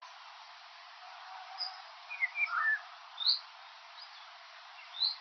Golden Oriole, Oriolus oriolus
StatusVoice, calls heard